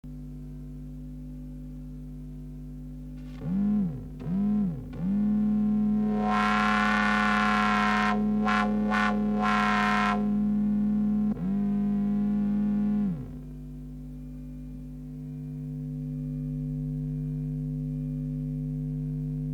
三拍子である。